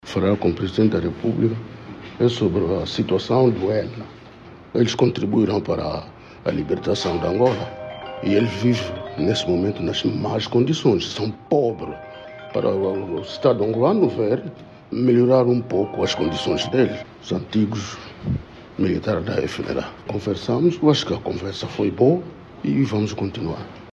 No final da audiência, Nimi a Simbi revelou à imprensa ter solicitado a intervenção directa do Chefe de Estado na melhoria das condições sociais desses antigos combatentes.